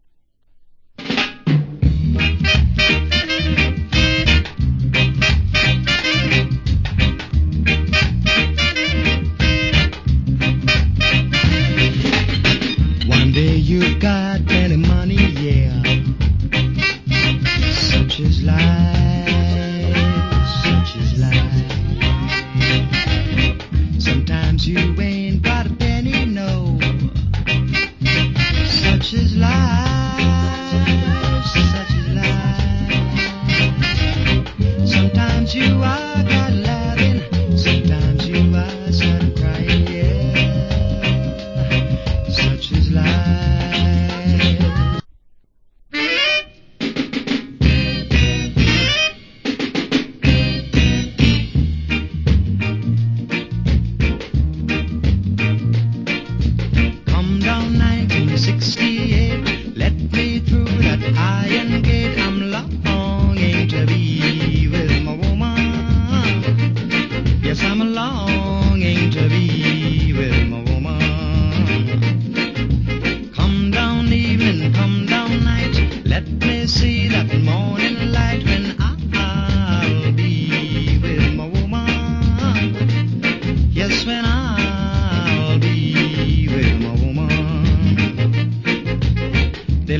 Wicked Rock Steady Vocal.